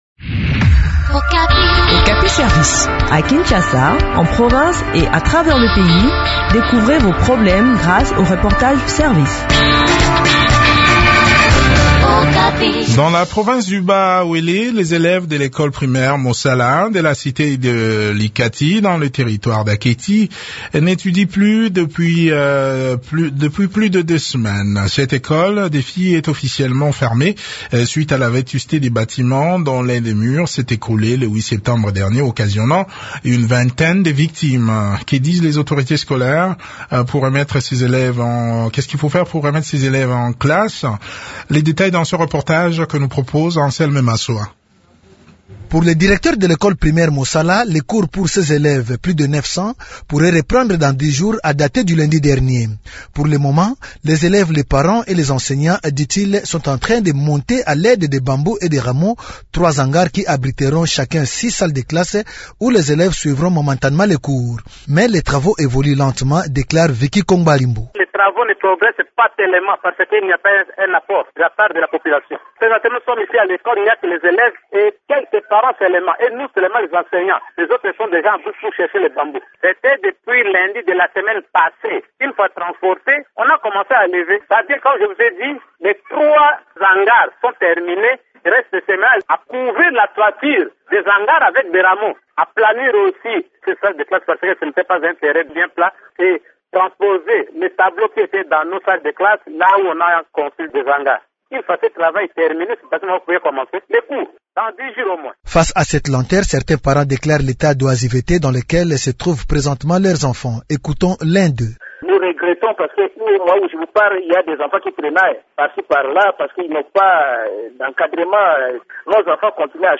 Alexis Adipandi, ministre provincial de l’EPS-INC du Bas-Uélé